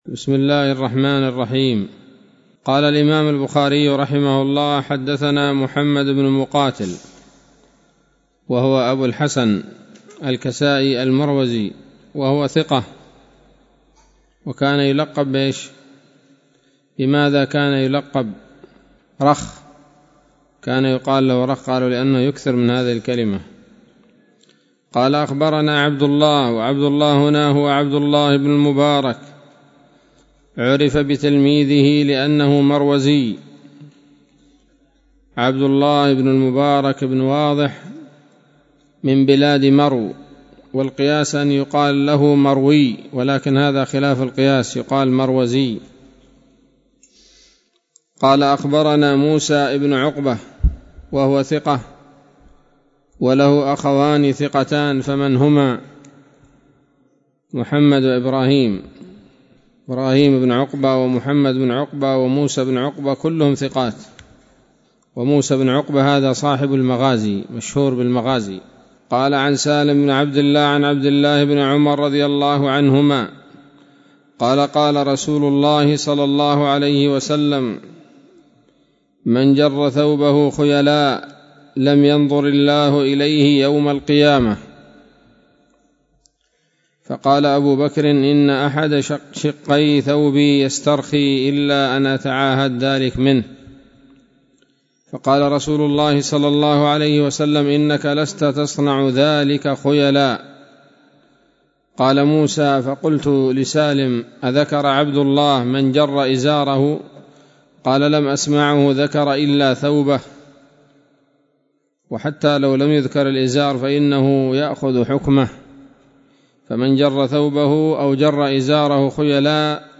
الدرس العاشر من كتاب فضائل أصحاب النبي صلى الله عليه وسلم من صحيح البخاري